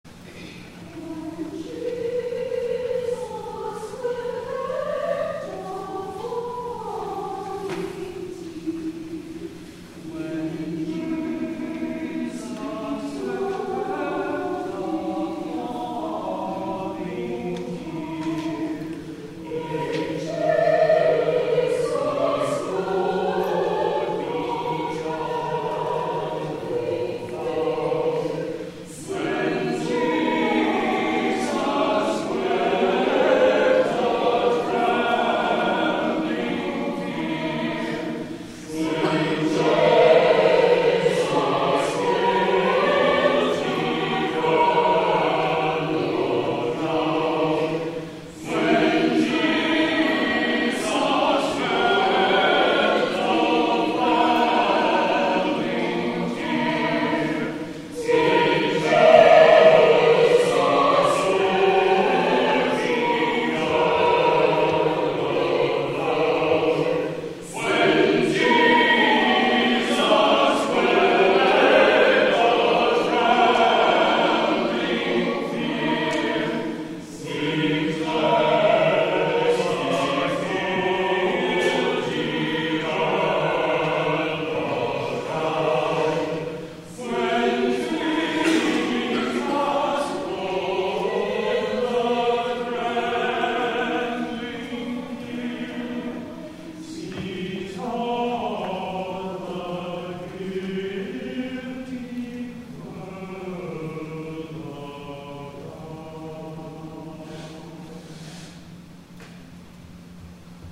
THE ANTHEM
William Billings has been described as "America's first significant composer" - his setting of "When Jesus wept" is a canon, or round, and illustrates the musicality he can achieve even within the simplest forms.